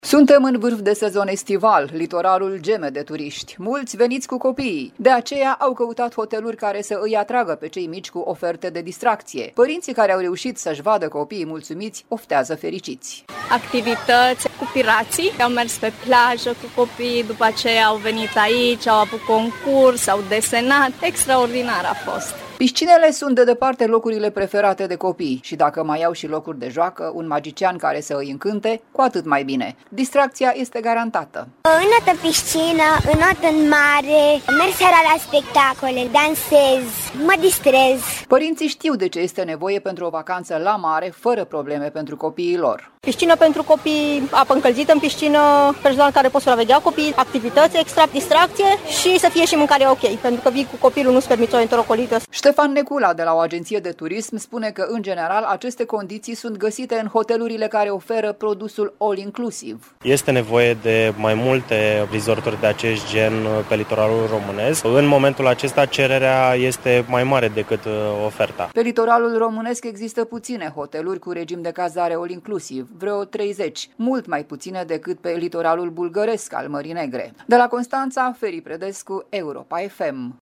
“Piscină pentru copii, apă încălzită în piscină, personal care să poată supraveghea copiii dacă e cazul, activități extra, distracție și să fie și mâncarea ok, pentru că vii cu copilul, nu-ți permiți o enterocolită”, afirmă o mamă.